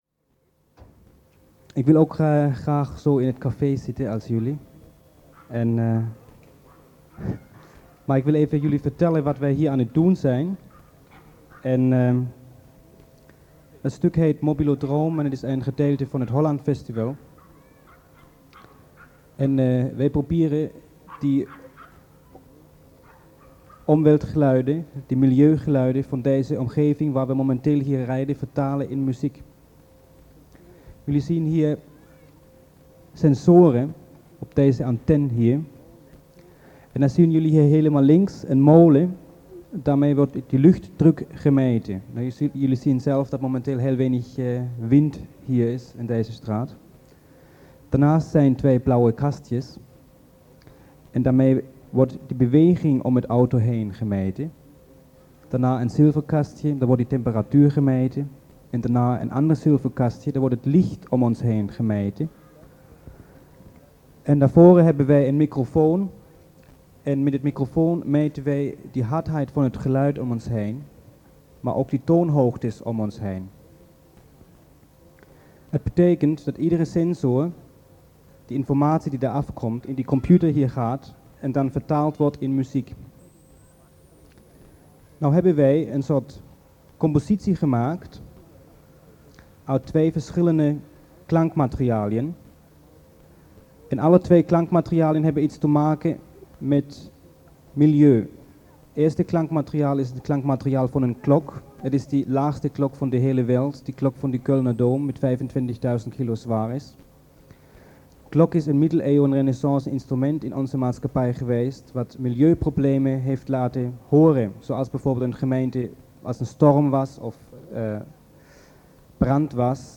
Vortrag-Utrecht-Mobilodrom.mp3